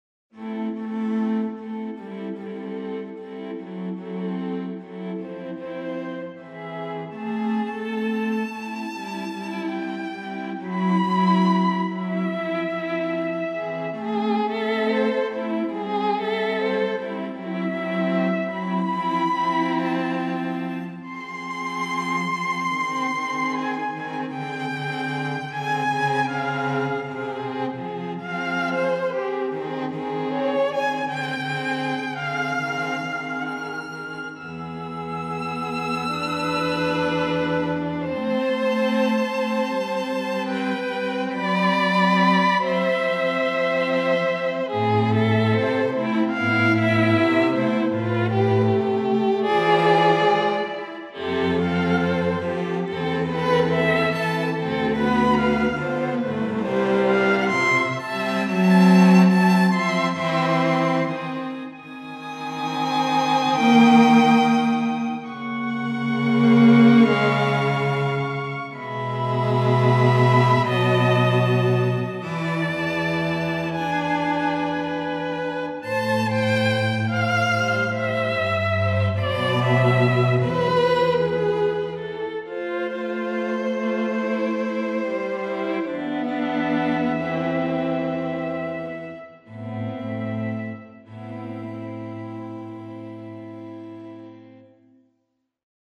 String Quartet demo
in SM Solo & Ensemble Strings